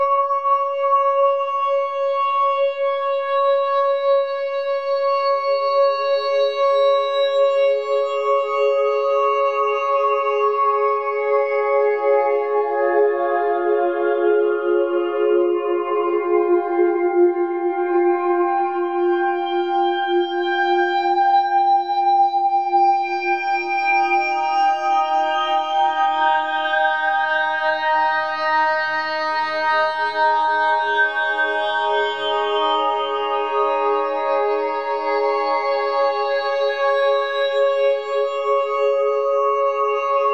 C#LYD PHAS.wav